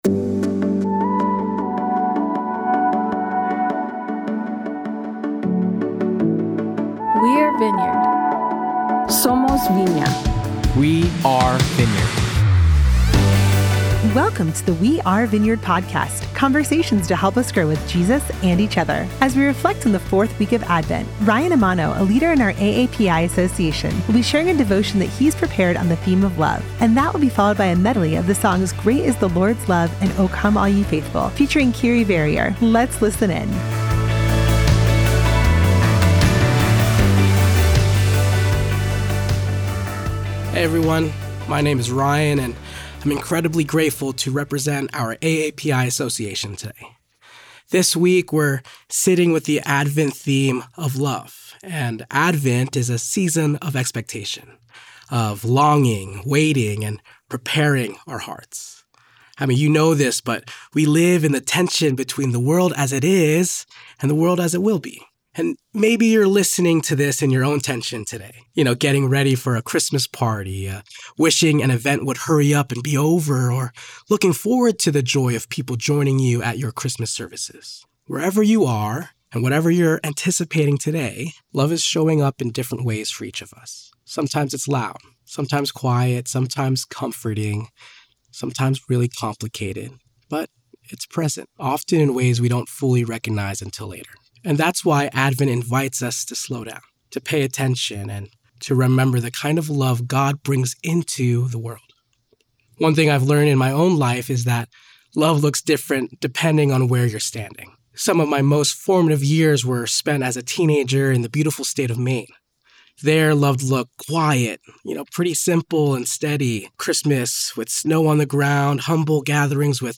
In celebration of the Advent season, you will hear a short devotion each week specially crafted for you by a member of one of Vineyard USA’s Associations. Today’s theme is Love